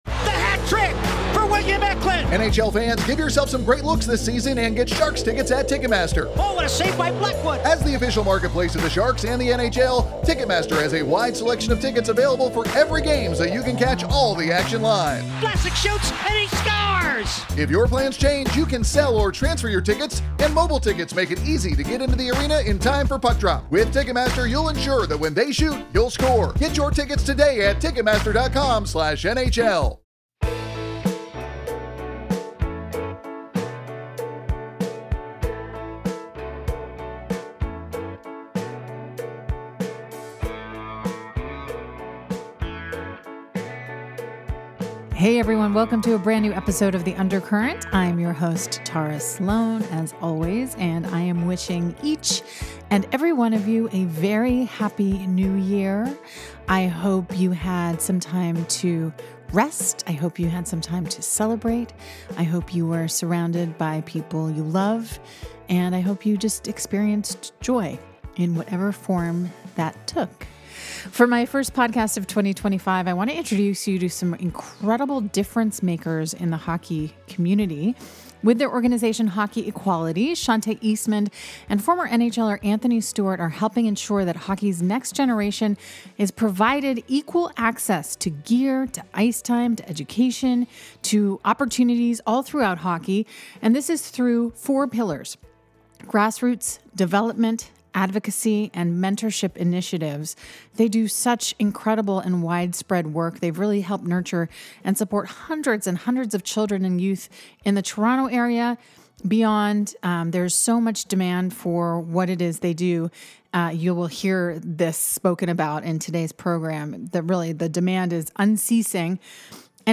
Broadcaster, musician, and talk show host Tara Slone talks with unique people around hockey and the San Jose Sharks community.